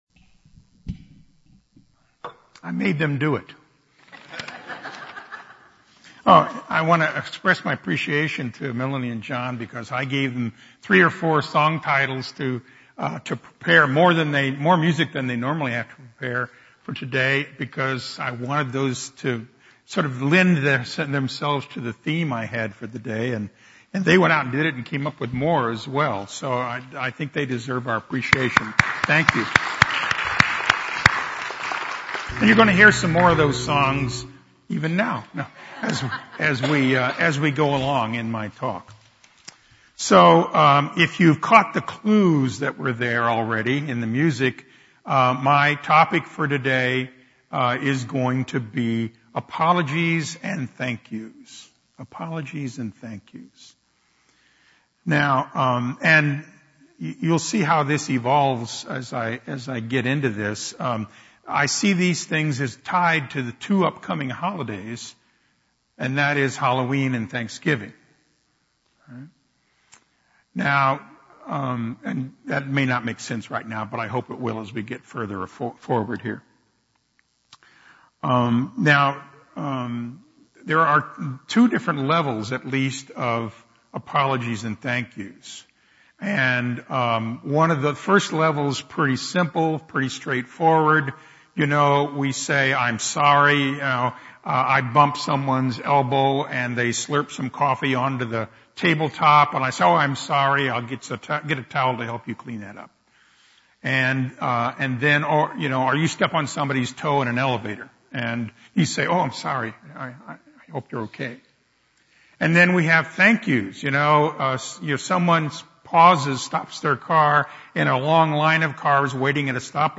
These are our Sunday messages, message quotes and guided meditations.